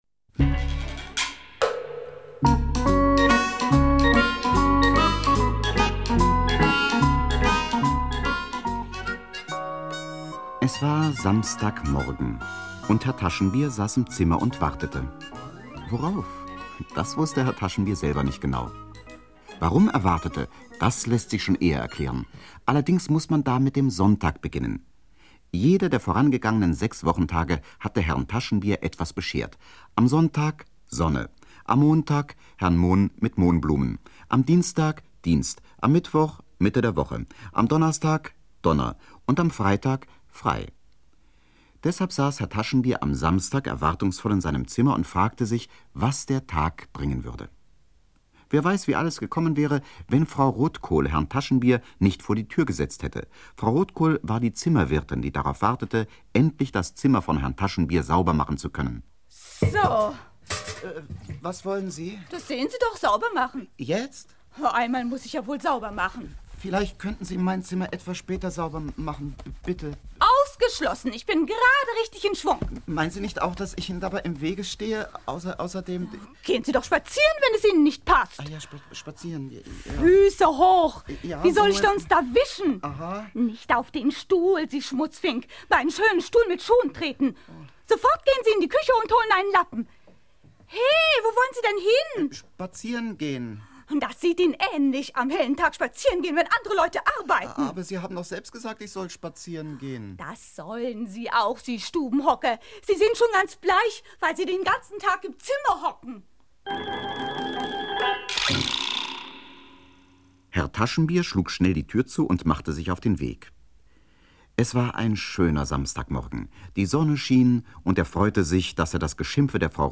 Das Sams. Die große Hörspielbox Paul Maar (Autor) Max Roth , Sabine Wüsthoff (Komponist) Audio-CD 2017 | 7.